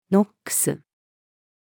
ＮＯｘ-female.mp3